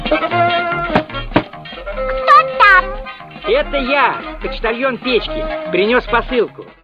Живые звуки, имитация